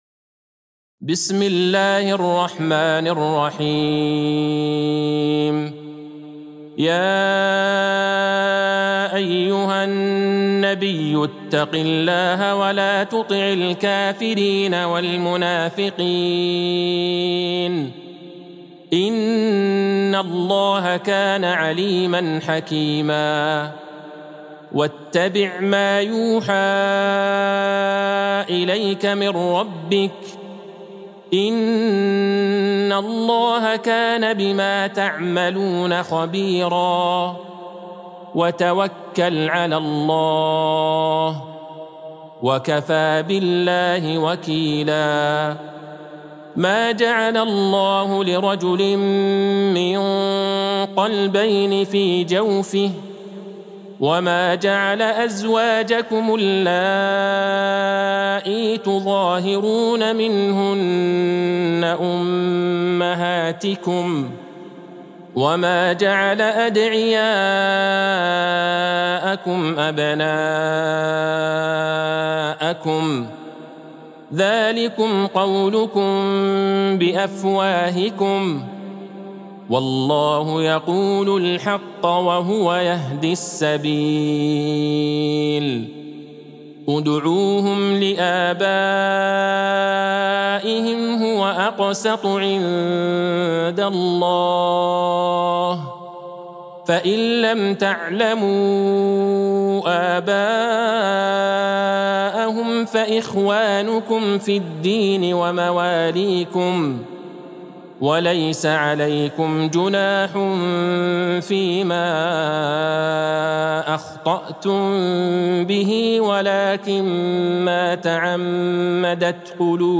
سورة الأحزاب Surat Al-Ahzab | مصحف المقارئ القرآنية > الختمة المرتلة ( مصحف المقارئ القرآنية) للشيخ عبدالله البعيجان > المصحف - تلاوات الحرمين